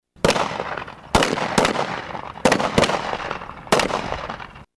Aproximativ 20 de jurnaliști mureșeni au răspuns invitației primite din partea celor 2 unități de jandarmi din județul Mureș și au luat parte la o nouă ediție a „Cupei Presei la Tir”.
După ce au trecut prin instructajul obligatoriu ziariștii au abătut o ploaie de gloanțe asupra inamicului, dar nu toate și-au atins ținta:
focuri-de-arma.mp3